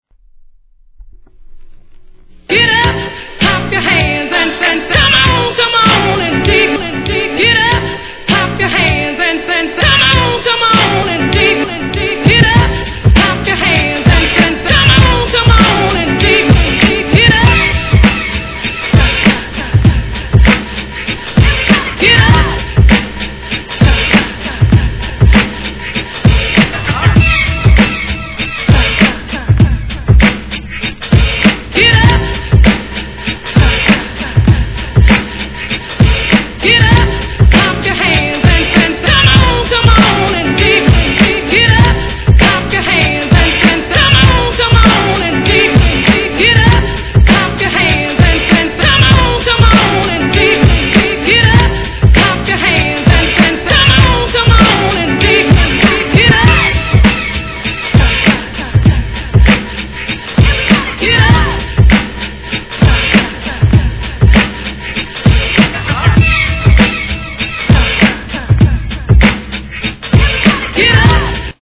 HIP HOP/R&B
定番PARTYブレイク・ビーツCLASSIC!!